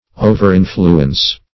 Search Result for " overinfluence" : The Collaborative International Dictionary of English v.0.48: Overinfluence \O`ver*in"flu*ence\, v. t. To influence in an excessive degree; to have undue influence over.